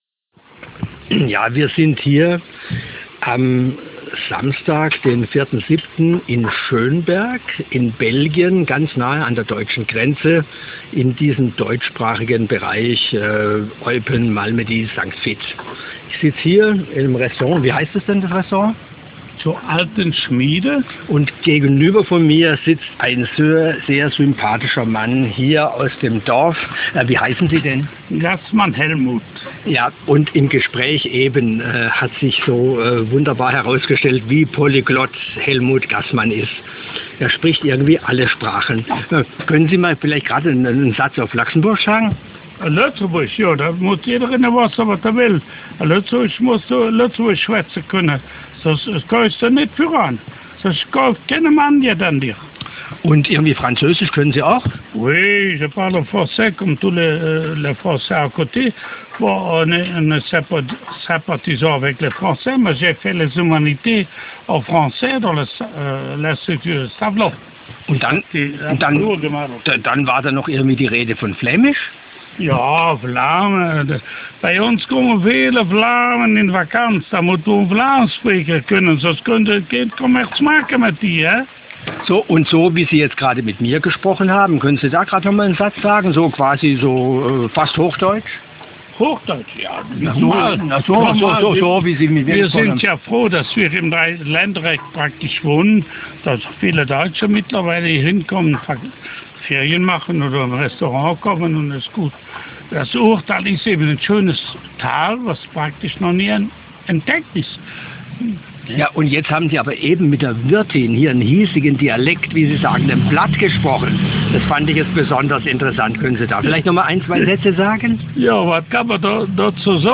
Interview1 *.mp3 (1.835 kb)